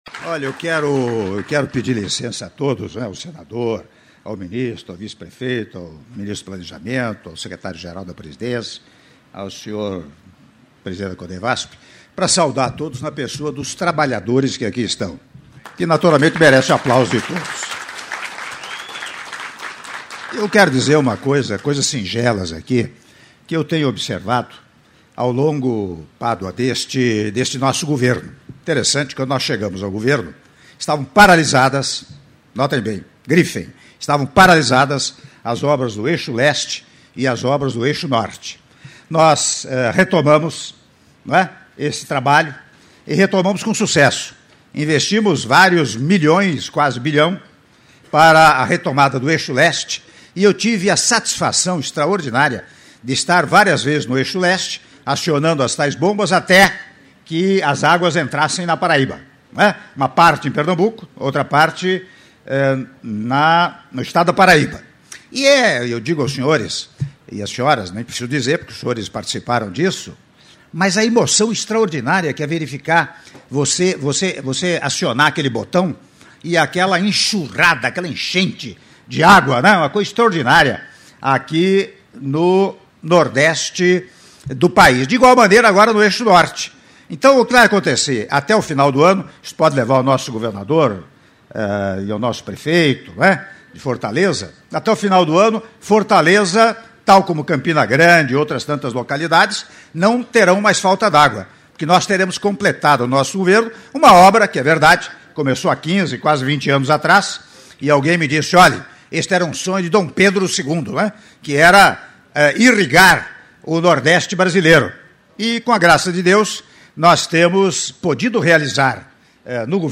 Áudio do discurso do Presidente da República, Michel Temer, durante Cerimônia Alusiva ao Acionamento da 3ª Estação de Bombeamento do Eixo Norte do Projeto de Integração do Rio São Francisco - Salgueiro/PE - (06min28s)